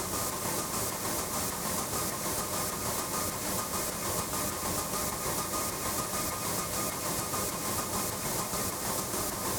STK_MovingNoiseB-100_02.wav